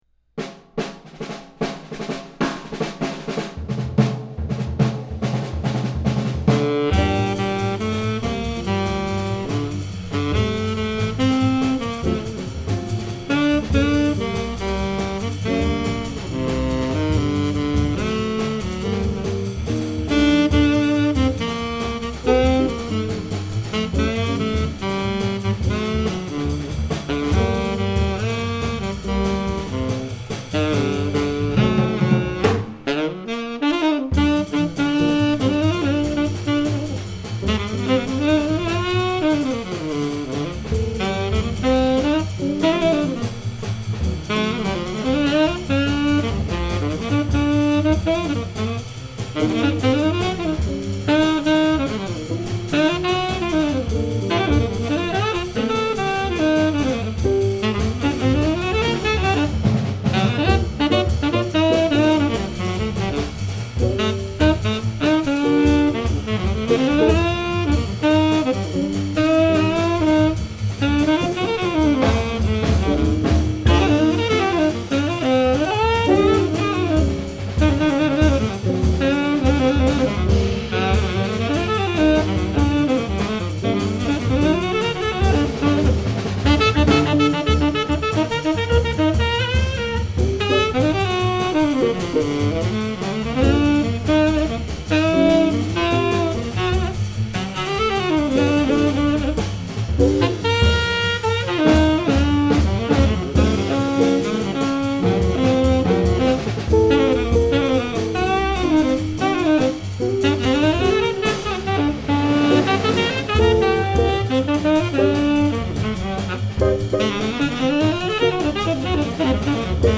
tenor saxophone
guitar
bass
drums